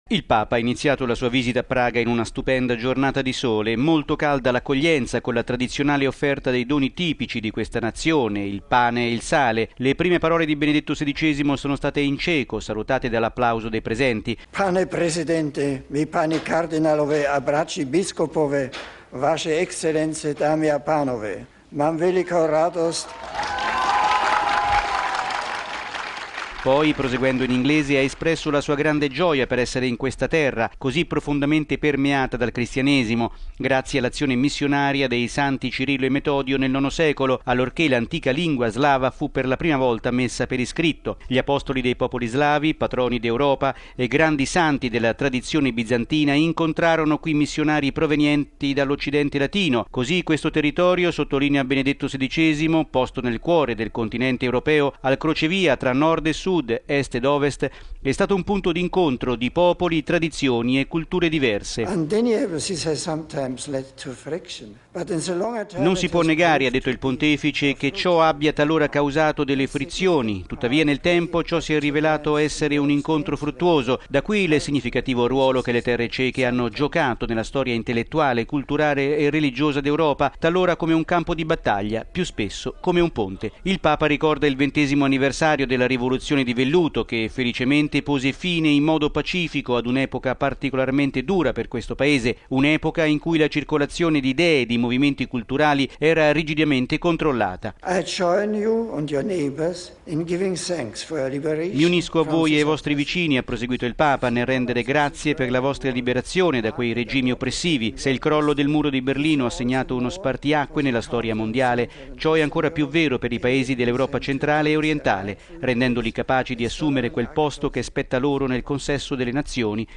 Le prime parole di Benedetto XVI sono state in ceco, salutate dall’applauso dei presenti: